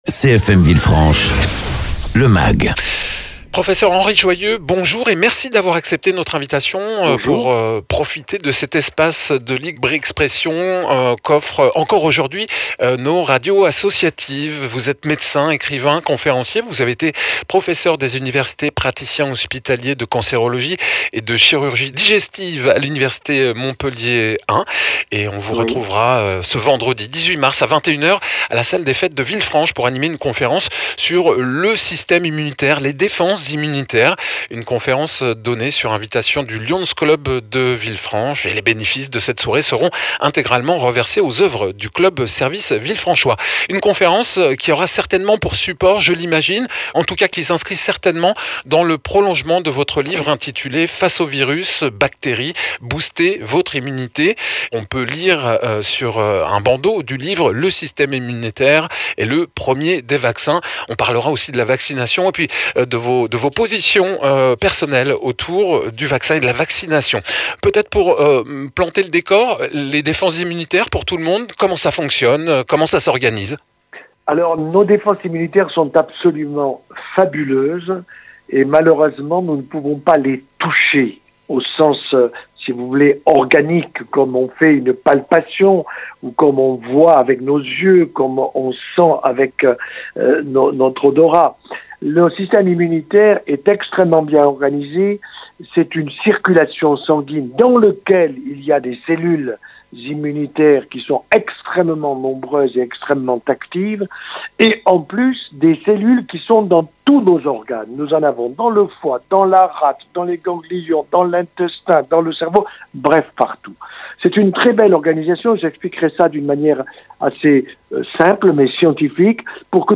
Interviews
Invité(s) : Henri Joyeux, médecin, écrivain, conférencier, ancien professeur de universités, praticien hospitalier de cancérologie et de chirurgie digestive.